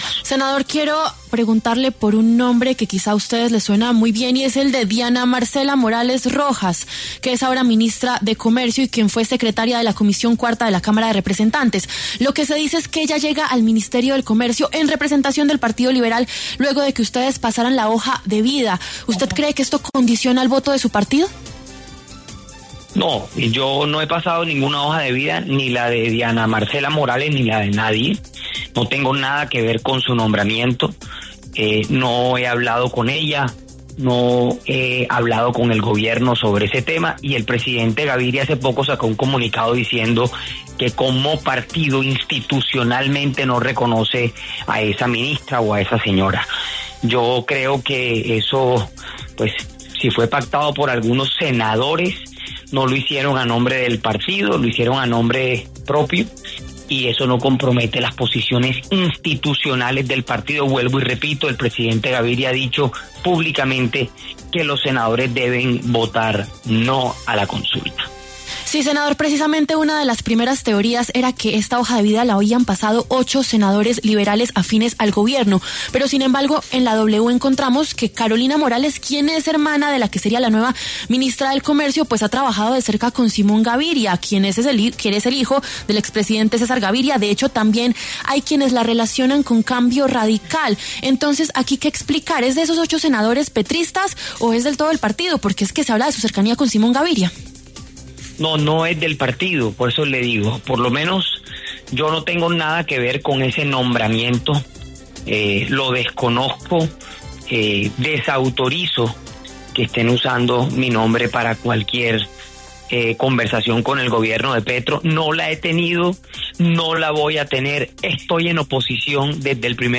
Mauricio Gómez Amín, senador del Partido Liberal, habló en W Fin De Semana sobre la designación de Diana Marcela Morales como ministra de Comercio.